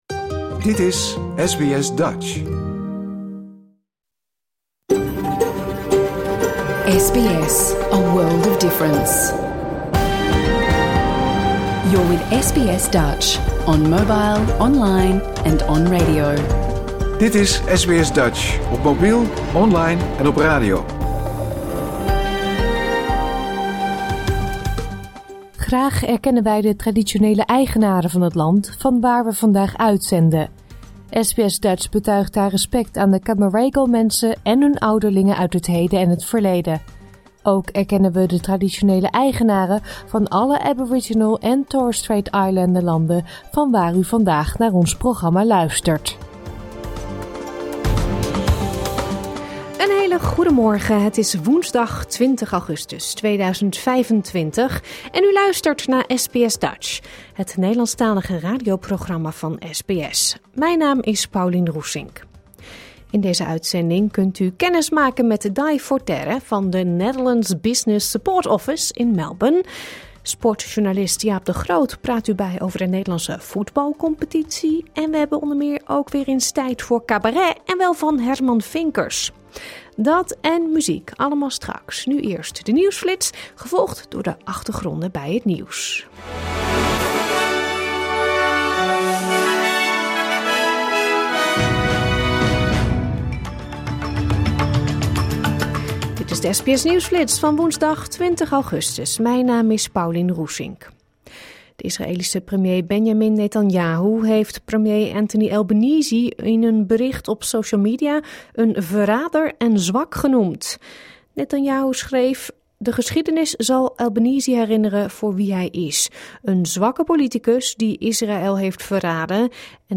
Terugluisteren: uitzending woensdag 20 augustus 2025
Luister hier de uitzending van woensdag 20 augustus 2025 (bijna) integraal terug.